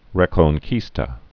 (rĕkōn-kēstə, -kən-)